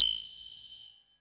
synTTE55016shortsyn-A.wav